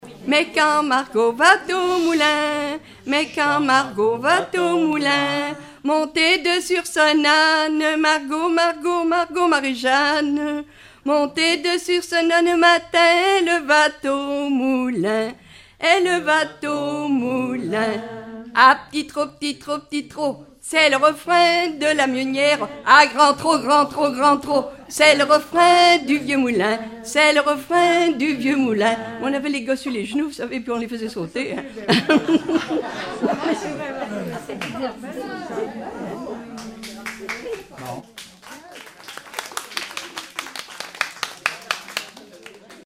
formulette enfantine : sauteuse
Répertoire de chansons populaires et traditionnelles
Pièce musicale inédite